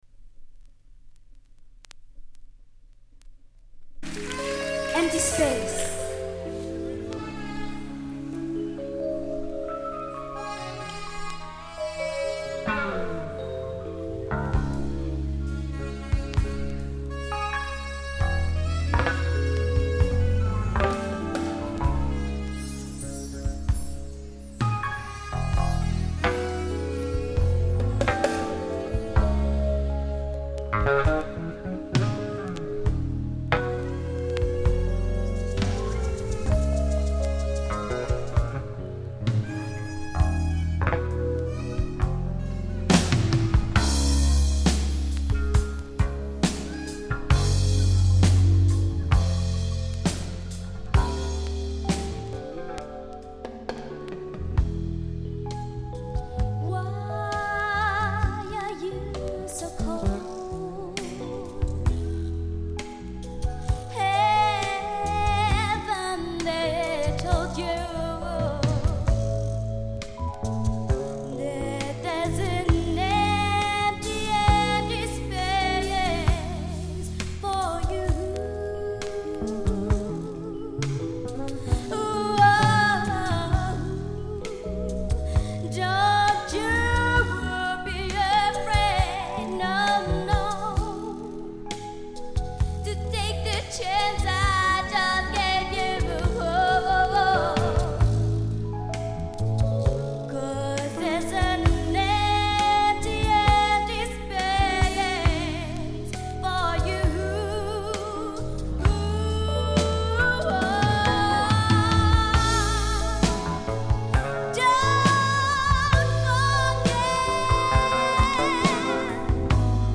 Zang & Percussie
Keyboards
Sax & add. keys
Conga ‘s & Handpercussie
Drums